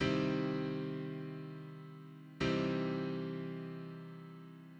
Two added chords with mixed thirds, thirds separated by octave.[6]